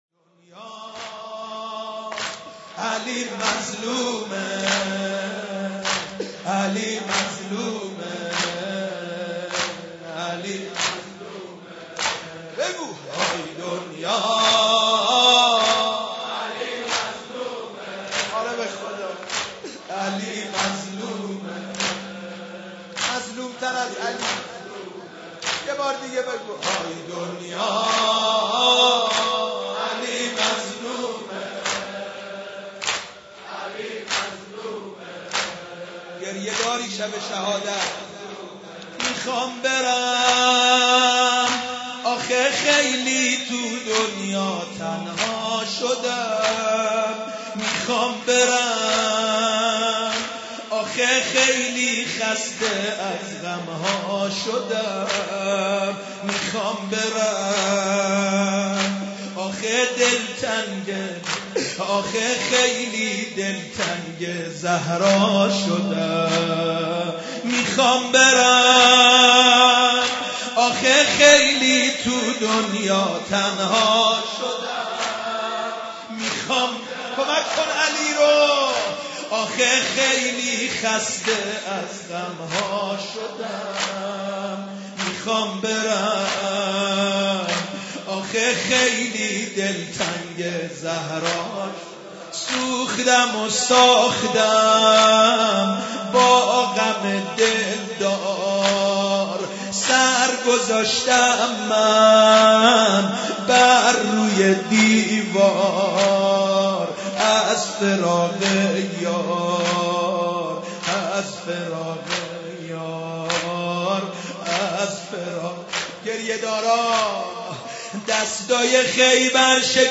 مداحی
در هیئت رزمندگان اسلام قم برگزار گردید.